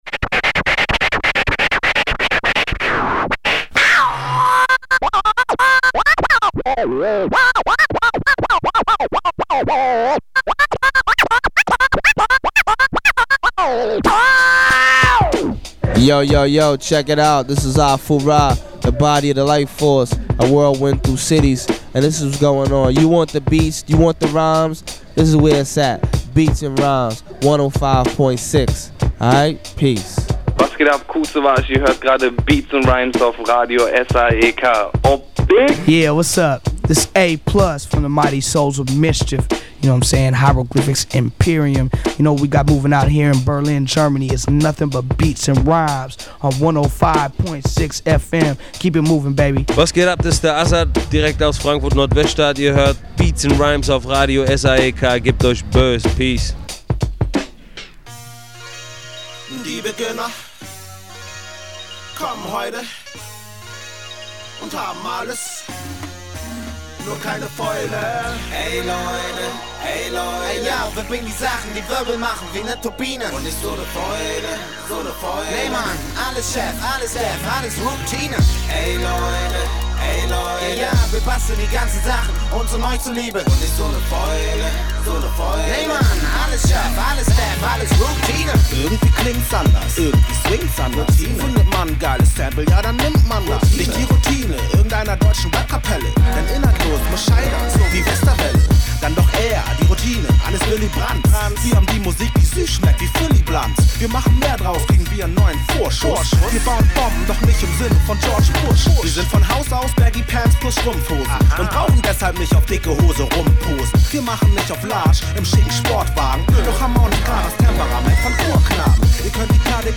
Inhalte: Interview mit CL Smooth beim Splash! 2004, Rückblick Splash! 2004, Musik